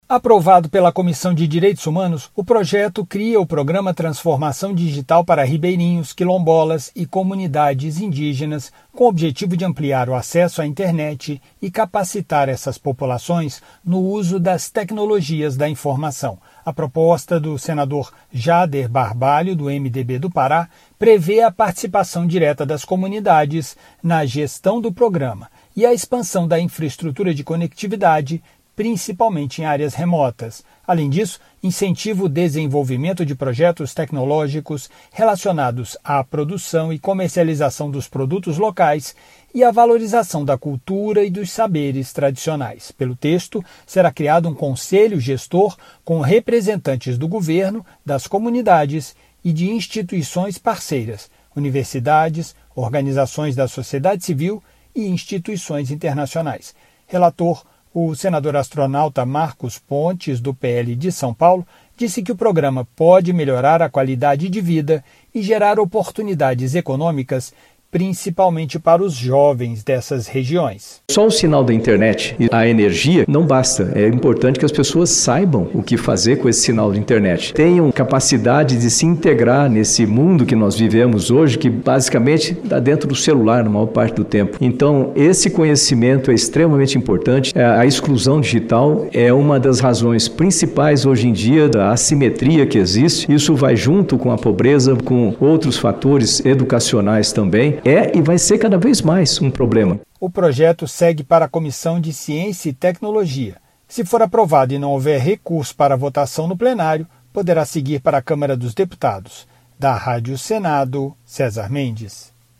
O objetivo é ampliar o acesso à internet e capacitar essas populações no uso das tecnologias da informação (PL 1153/2025). Relator, o senador Astronauta Marcos Pontes (PL-SP) disse a proposta vai gerar oportunidades econômicas para os jovens dessas regiões, reduzindo assimetrias e combatendo a pobreza.